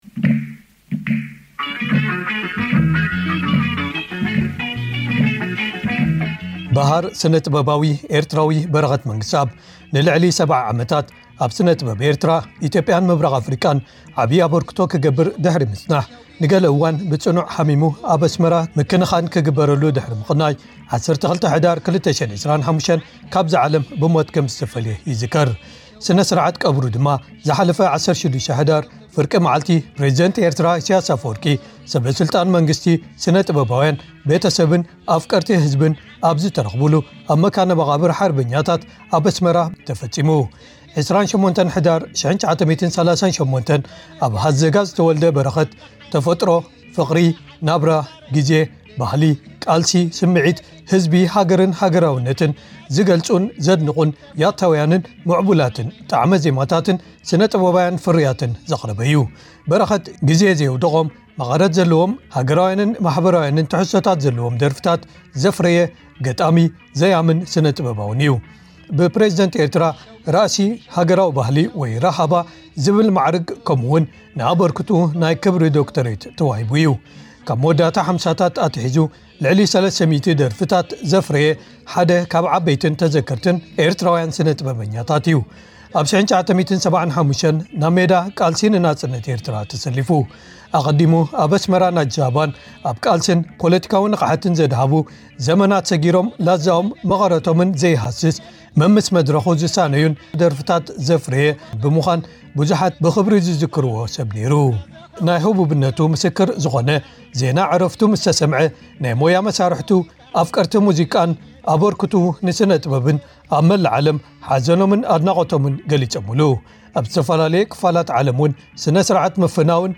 A memorial event was held for the late legendary Eritrean artist, Bereket Mengisteab, in Melbourne, Australia on Sunday 14 December 2025. It was jointly organized by Eritrean Australian Cultural Forum and Eritrean Community in Australia (ECA).